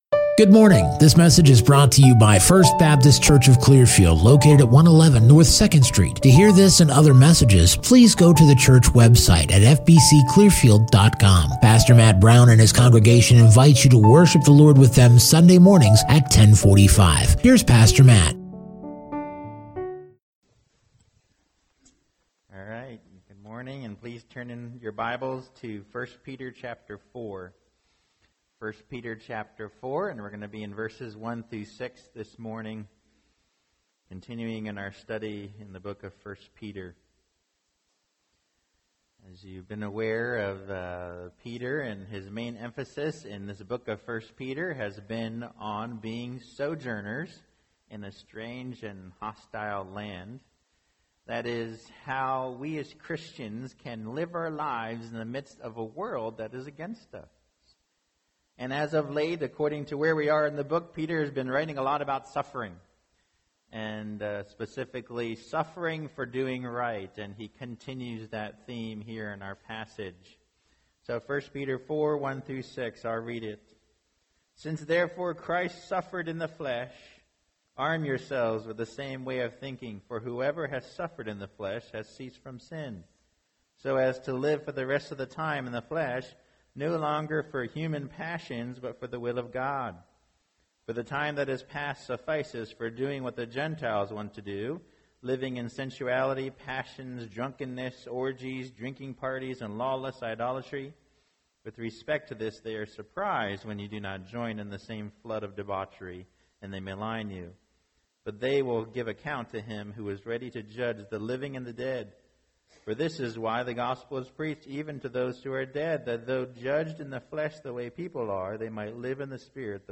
2021 Armed for Suffering Preacher